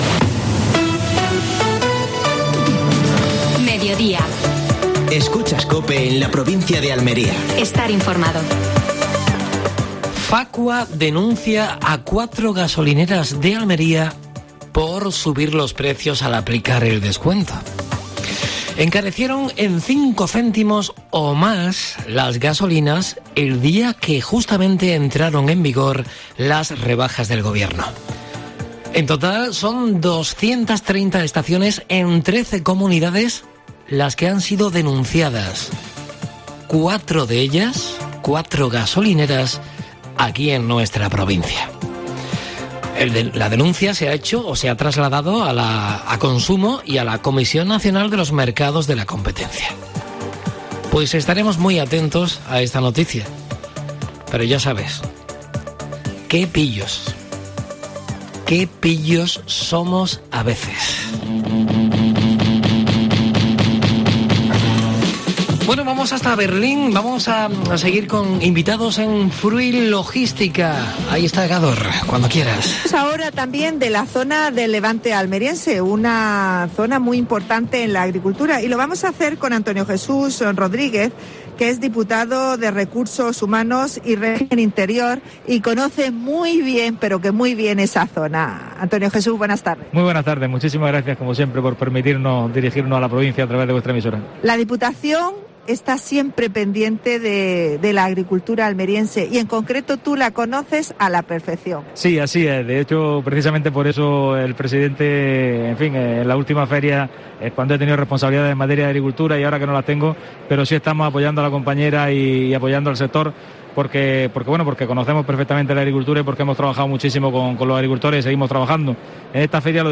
Actualidad en Almería. Entrevista a Antonio Jesús Rodríguez (diputado provincial).